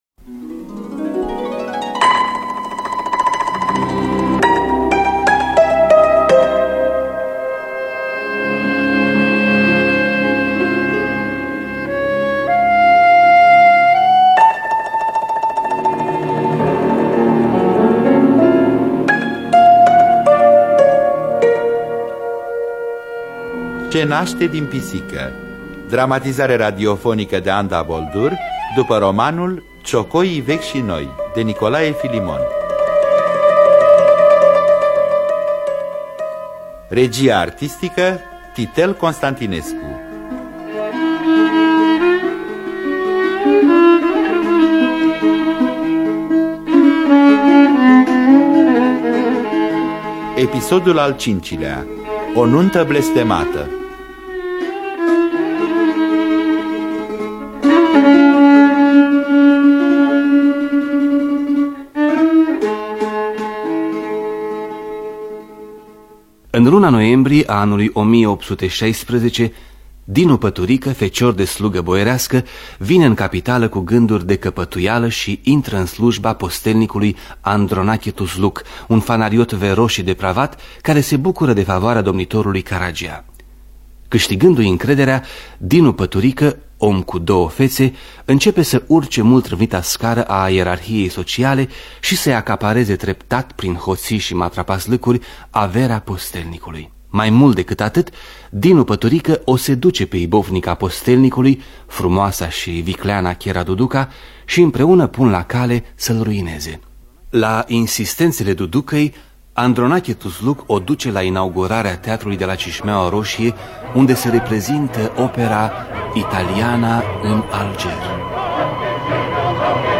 Nicolae Filimon – Ce Naste Din Pisica (1980) – Episodul 5 – Teatru Radiofonic Online